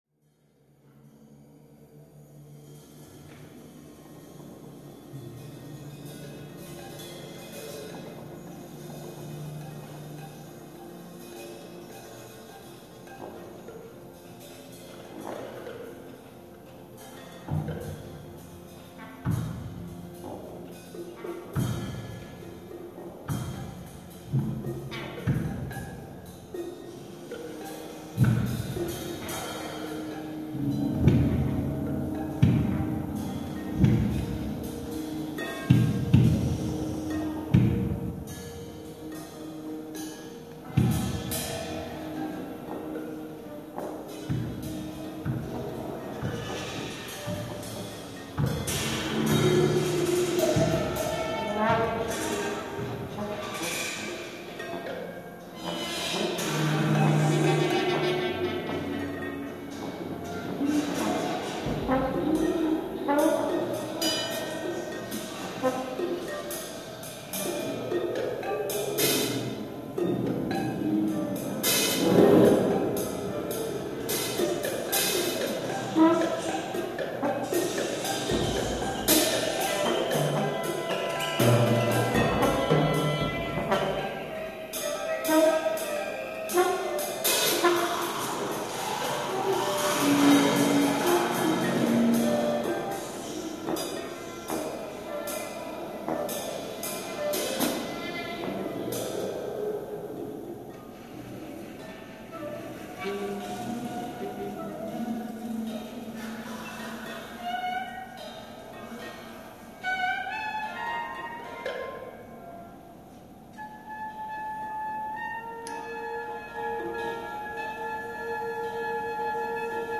Live in Bologna
All files are 64kb/s, MONO audio.